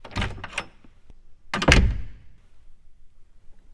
the-sound-of-opening-and-closing-the-door